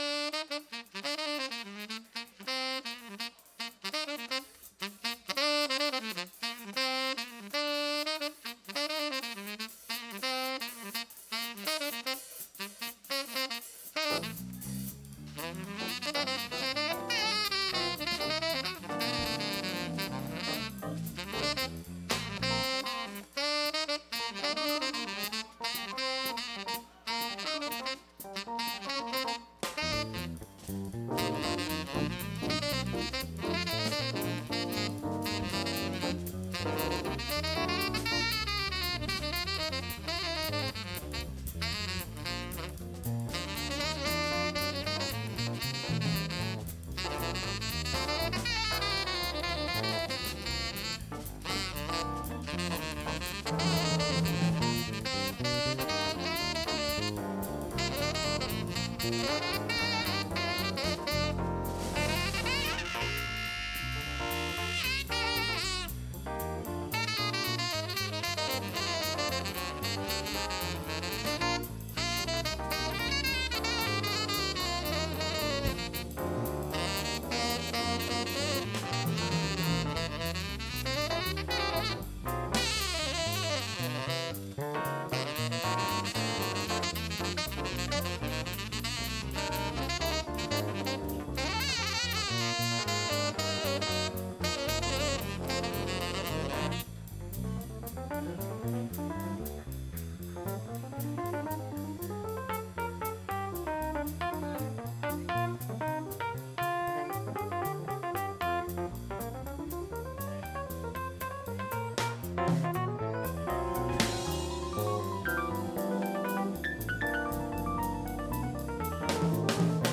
Fusion Coffeehouse - live jazz every month and the Township Jazz Festival every April